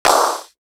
Sizzle Pitch.wav